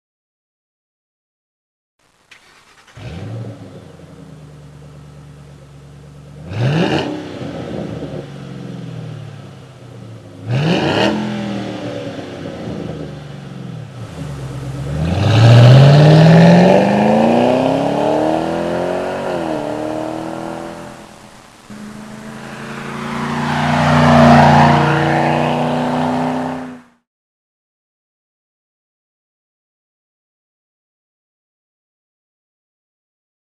Recommended for Aggressive Sound Sound Test
ExtremeSound.mp3